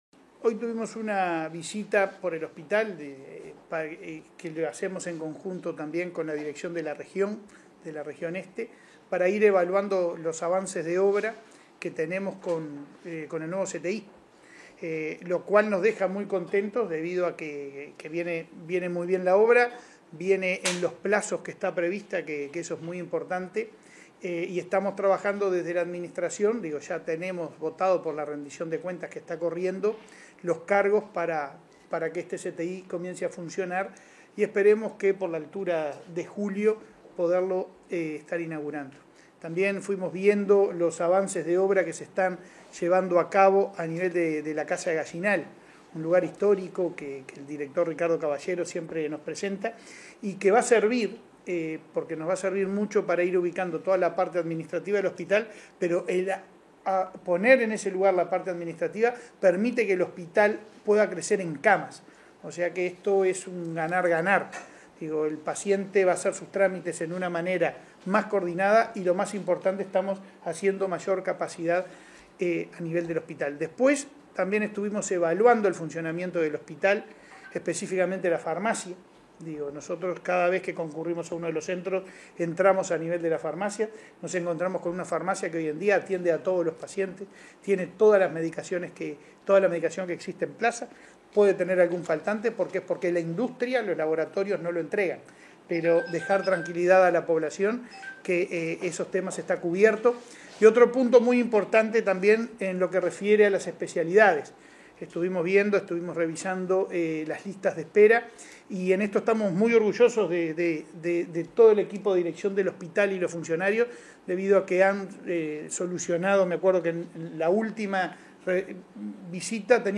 Palabras de los presidentes de ASSE y Cudim
Palabras de los presidentes de ASSE y Cudim 15/02/2023 Compartir Facebook X Copiar enlace WhatsApp LinkedIn En el marco de la visita al hospital departamental de Cerro Largo, se expresaron el presidente de la Administración de los Servicios de Salud del Estado, Leonardo Cipriani, y del Centro de Imagenología Molecular (Cudim), Pablo Duarte.